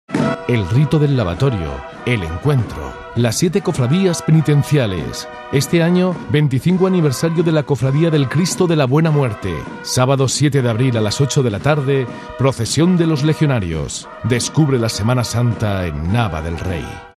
SEMANA SANTA NAVA_Nava del Rey (publicidad SER Medina).mp3